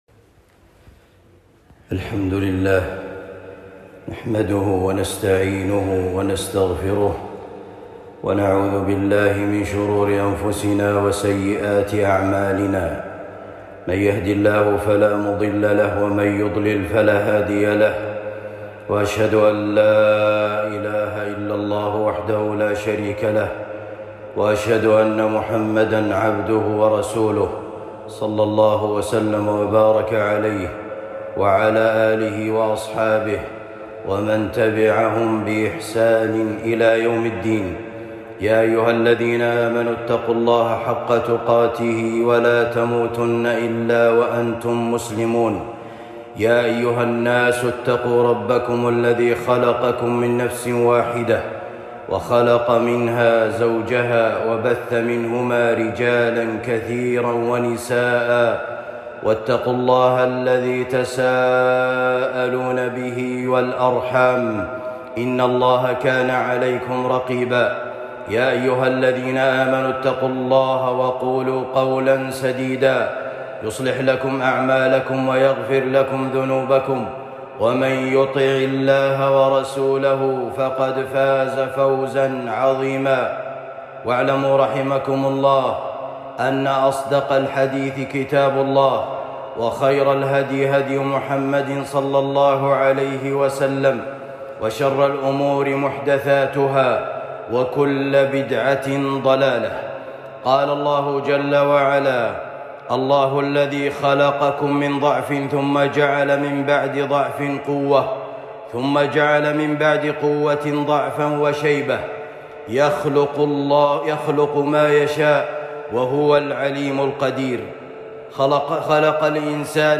حقوق كبار السن خطبة جمعة